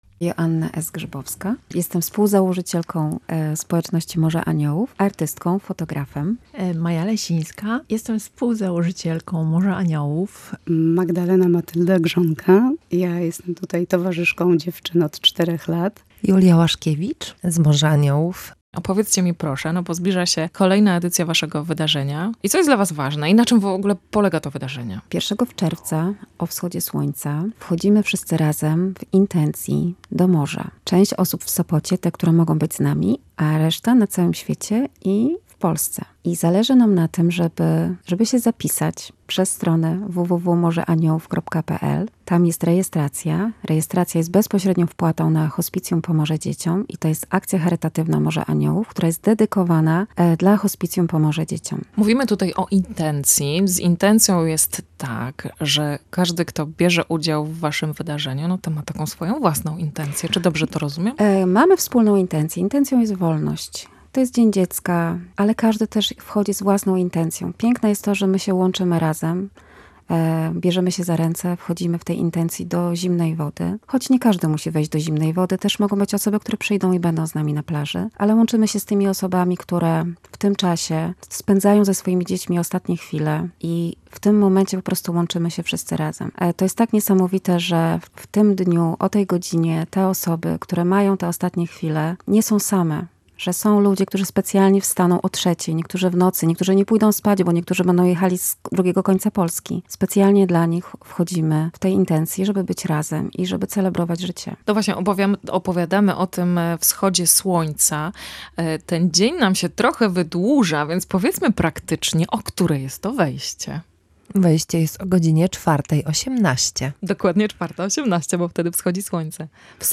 O inicjatywie w audycji „Radio Trendy” opowiadają organizatorki z Morza Aniołów.